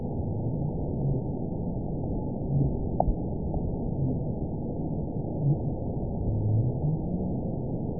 event 917637 date 04/11/23 time 00:40:48 GMT (2 years, 1 month ago) score 9.49 location TSS-AB01 detected by nrw target species NRW annotations +NRW Spectrogram: Frequency (kHz) vs. Time (s) audio not available .wav